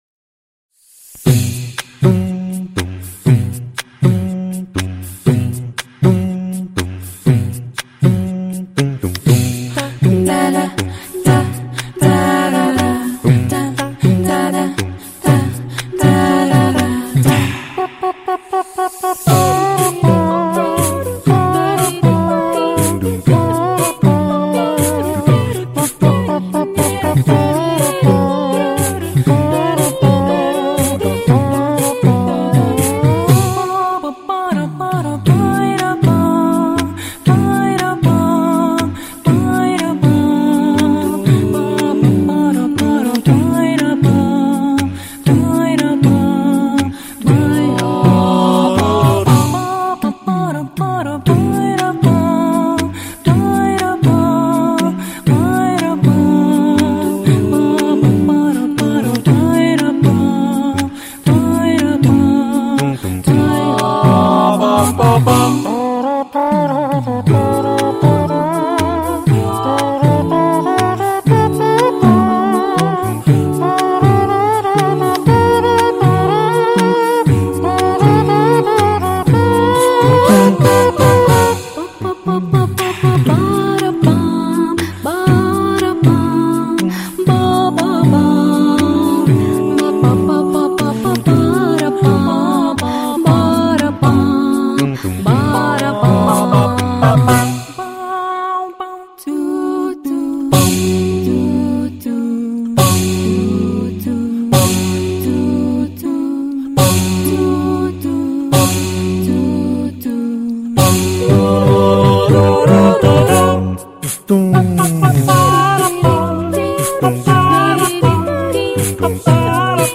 موسیقی: آکاپلا، صدای ساز از حنجره‌ی انسان